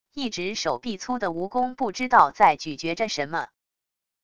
一直手臂粗的蜈蚣不知道在咀嚼着什么wav音频